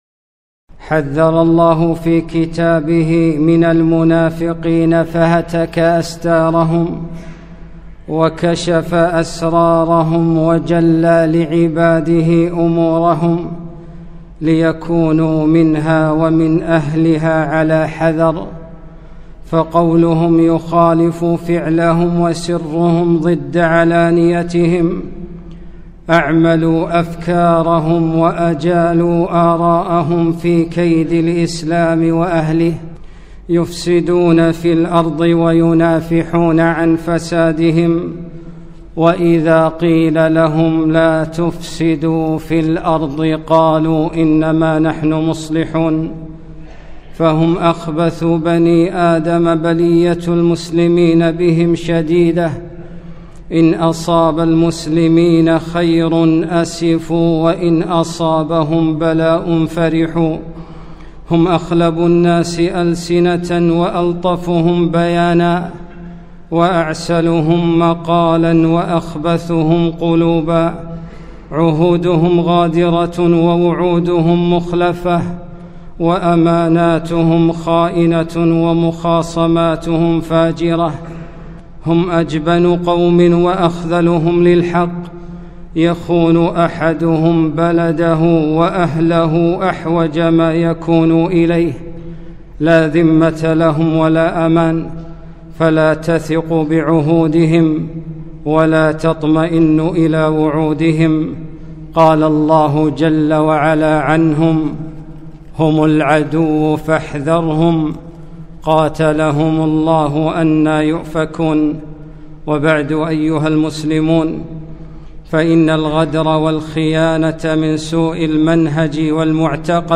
خطبة - خونة الأوطان